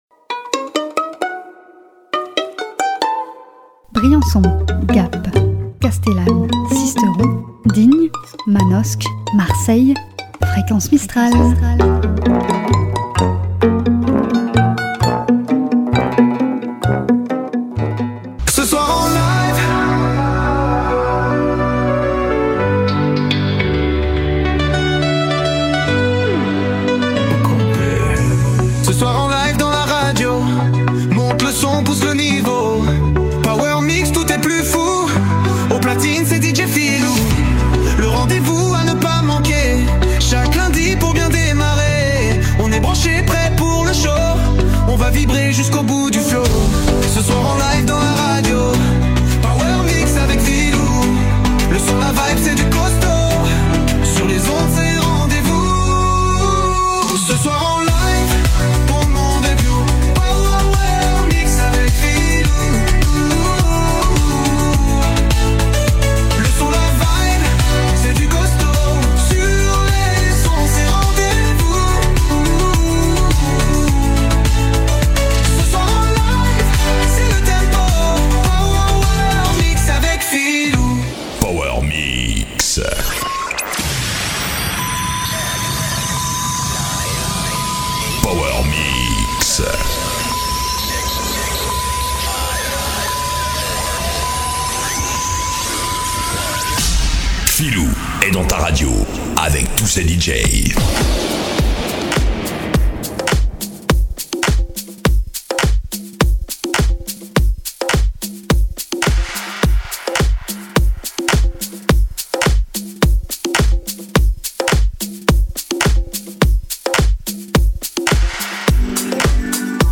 la communauté des DJ's (Men and Women)